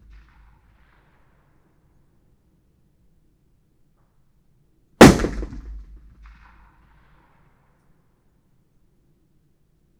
Environmental
Streetsounds
Noisepollution
UrbanSoundsNew / 01_gunshot /shot556_168_ch01_180718_164345_05_.wav